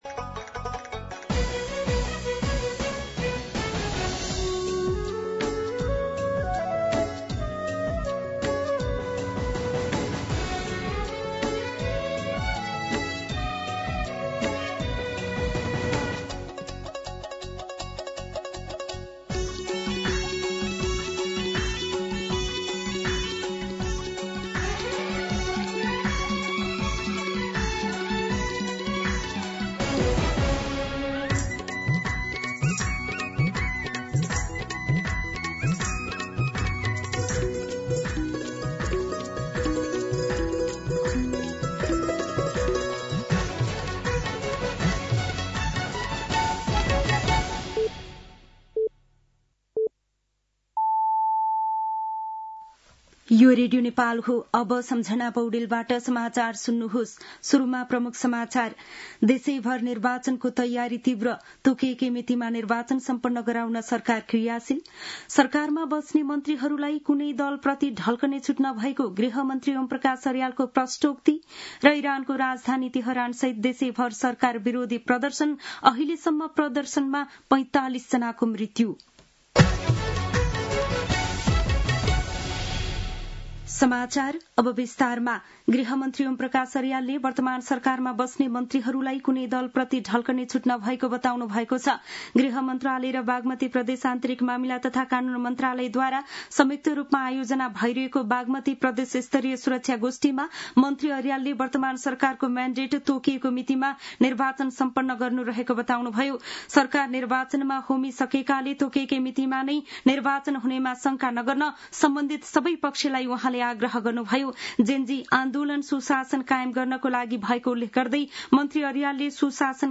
दिउँसो ३ बजेको नेपाली समाचार : २५ पुष , २०८२
3-pm-News-9-25.mp3